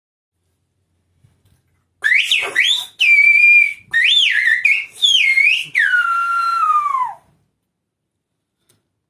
Tot sovint, malgrat tots els esforços, arriba un moment en què el receptor no comprèn part del missatge que se li transmet i és llavors quan apareix en el xiulet una fórmula molt important, la fórmula “Yo no te entiendo,  ¿Qué dijiste, que yo no te entiendo?”